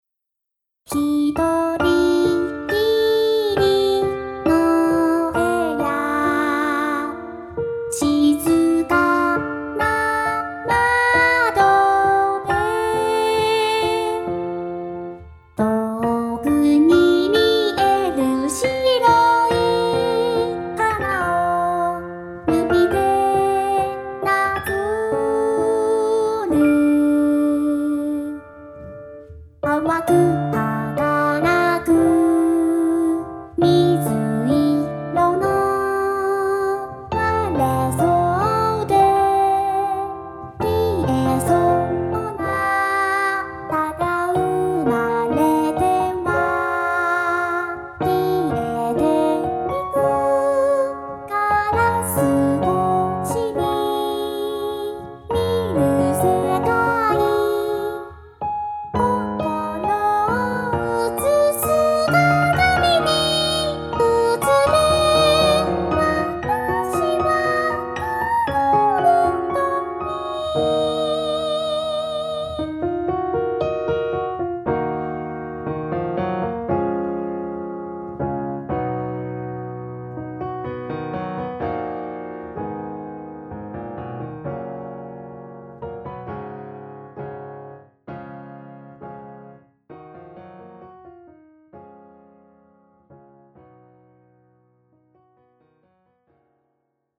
ピアノ弾き語り風ですが、ボカロ版、思っていたよりいい感じ。
ピアノ伴奏とメロは完成。